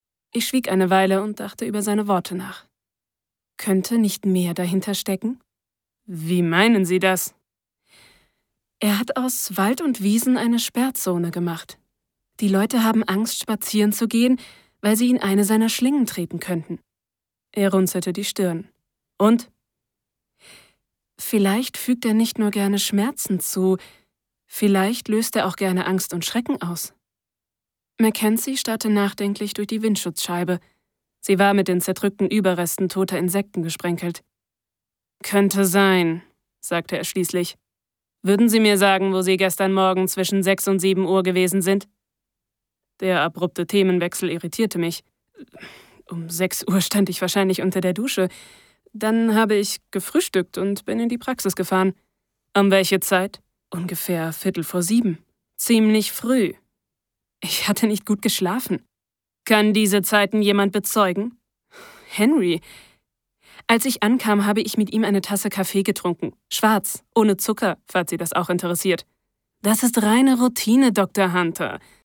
Eigenes prof. Studio vorhanden (Neumann TLM 103)
Sprechprobe: Sonstiges (Muttersprache):